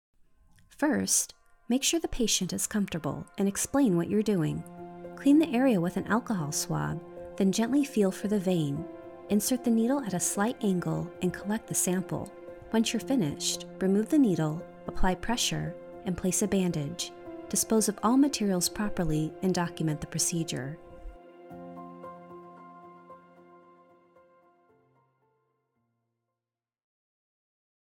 Voice-over artist with a warm, articulate, and soothing voice that brings calm and clarity to every project
Blood Test Explainer-Instructional